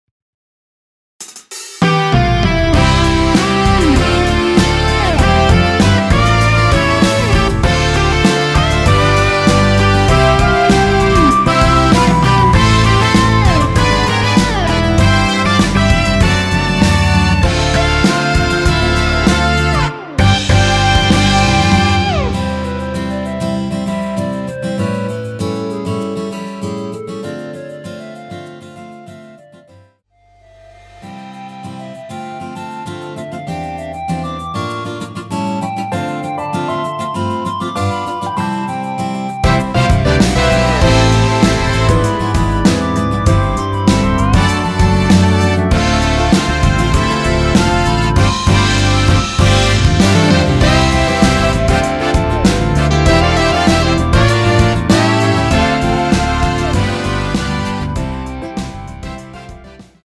원키 멜로디 포함된 MR입니다.(미리듣기 확인)
앞부분30초, 뒷부분30초씩 편집해서 올려 드리고 있습니다.